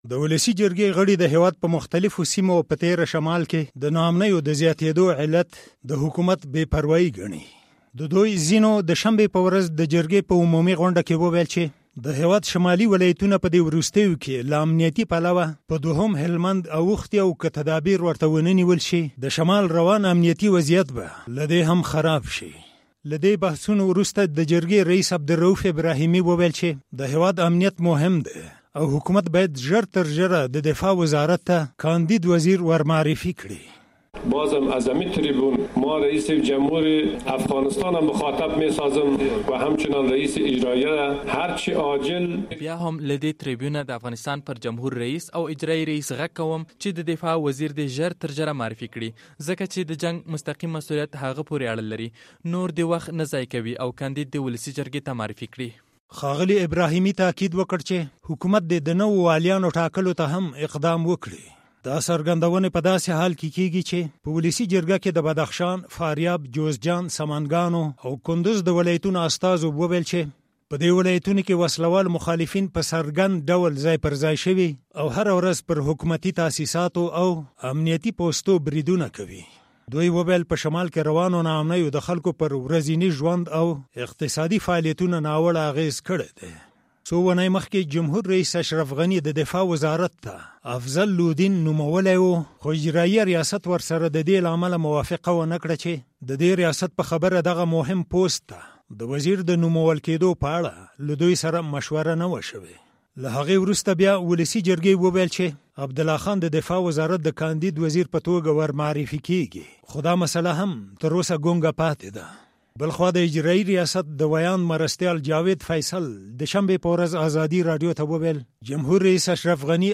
راپورونه